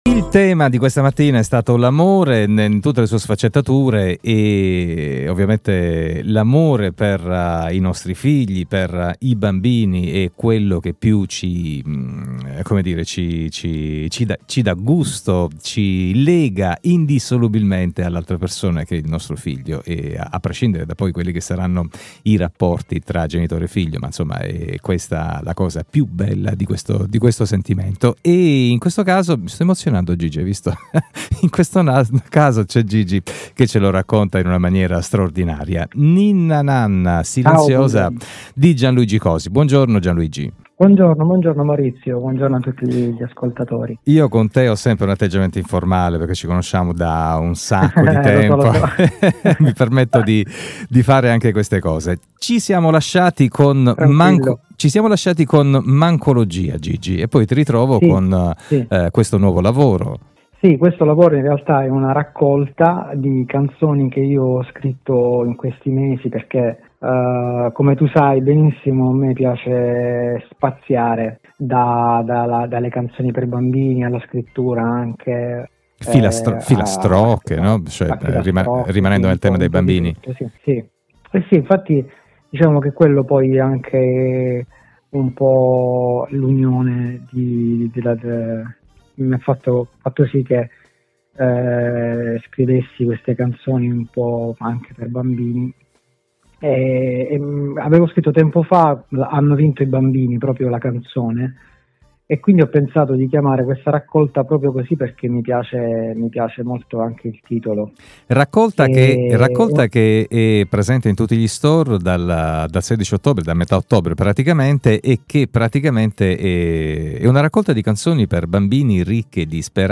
Una bella chiacchierata